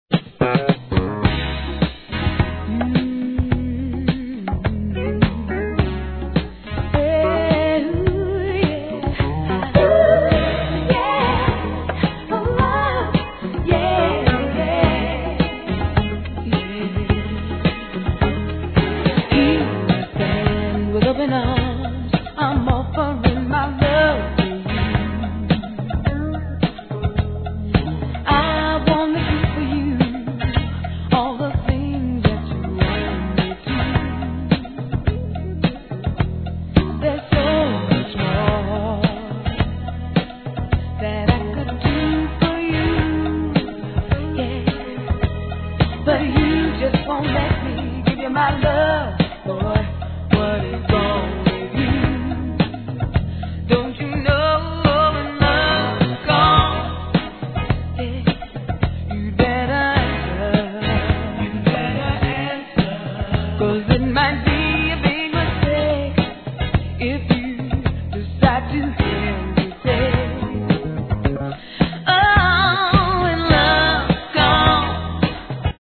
¥ 880 税込 関連カテゴリ SOUL/FUNK/etc...
アーバンなミディアム